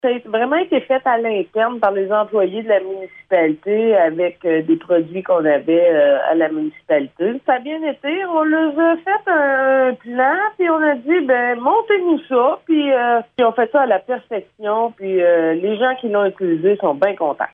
Depuis le début de l’été, la population a maintenant accès à une piste pour les vélos de type BMX. La piste est située au parc municipal de Déléage et est maintenant en fonction. La mairesse de Déléage, Anne Potvin, en dit plus :